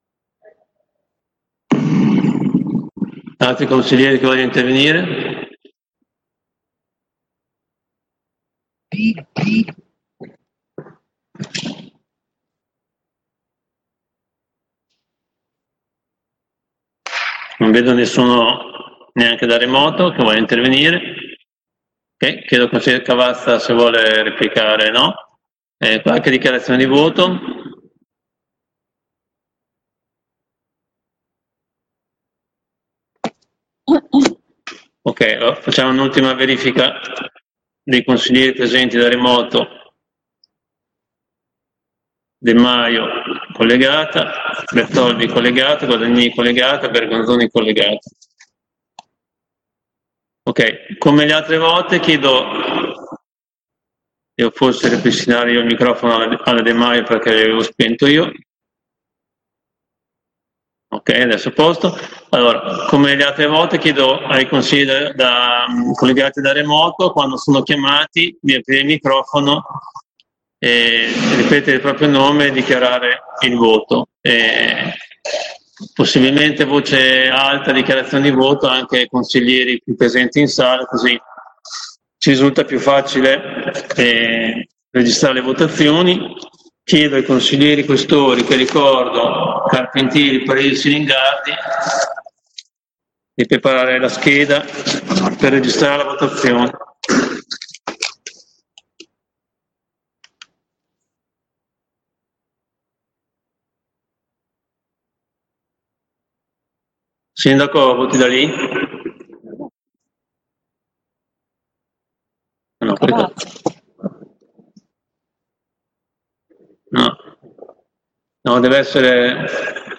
Fabio Poggi — Sito Audio Consiglio Comunale
Seduta del 28/05/2020 Mette ai voti Delibera.